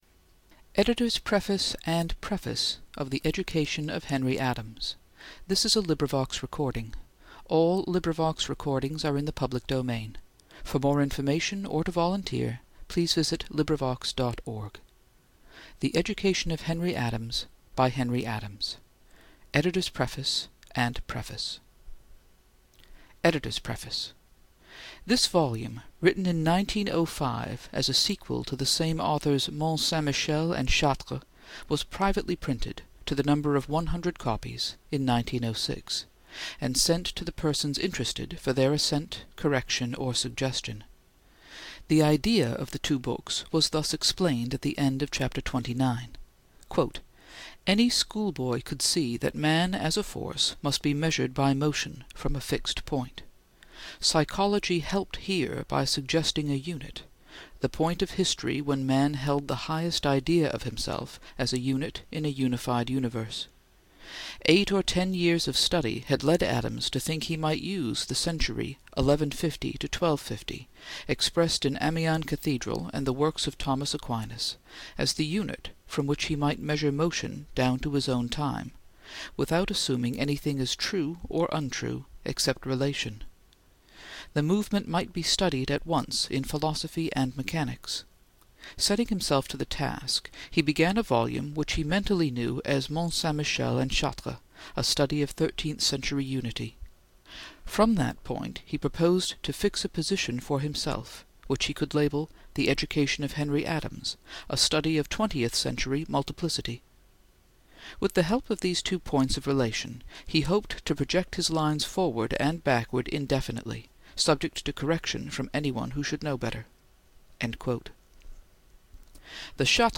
English: Prefaces to The Education of Henry Adams (1918) by Henry Adams, read aloud for LibriVox.